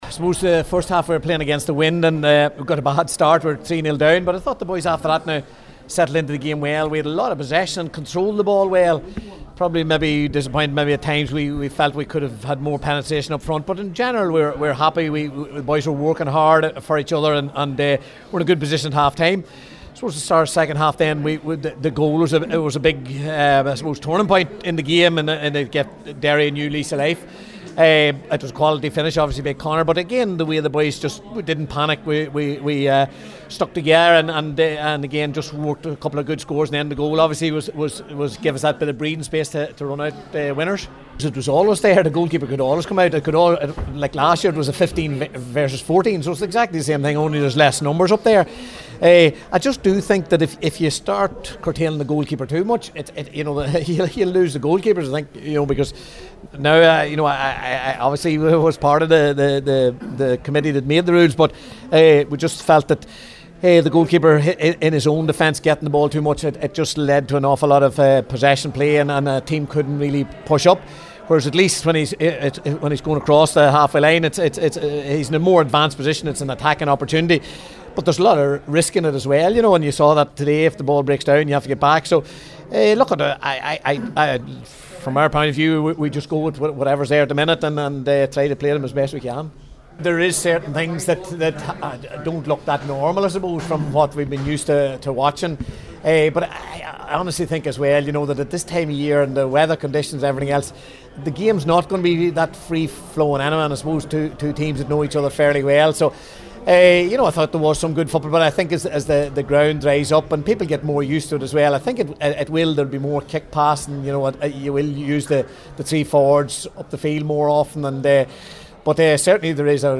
After the game, Tyrone manager Malachy O’Rourke spoke to the assembled media…